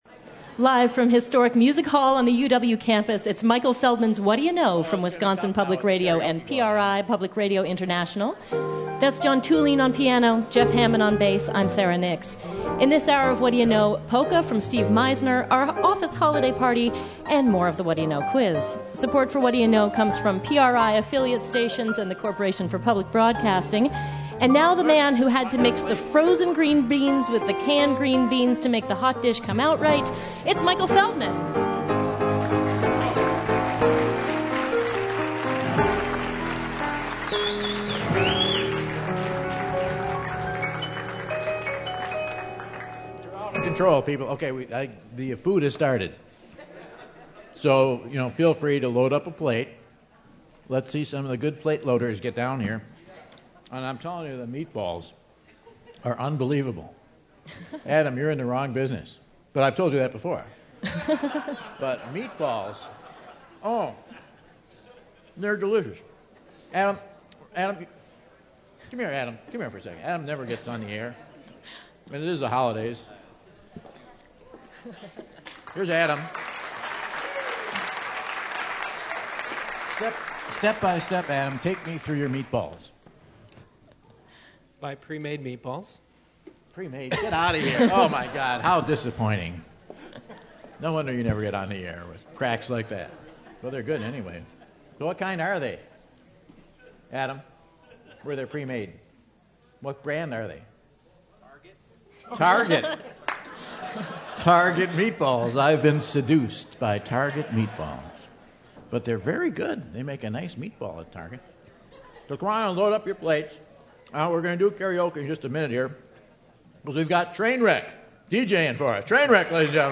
the fans flood the stage to take over the mic for a bit!